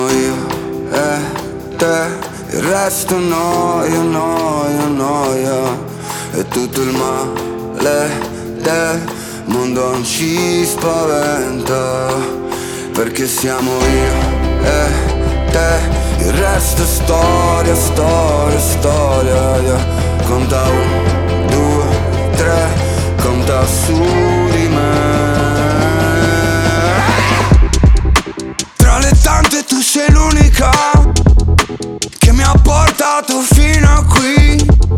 Скачать припев
2025-05-09 Жанр: Поп музыка Длительность